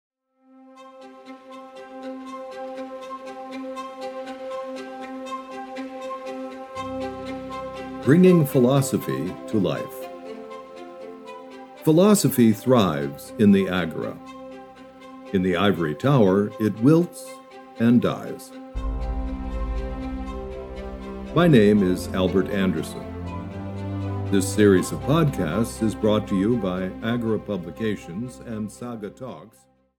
Censorship (EN) audiokniha
Ukázka z knihy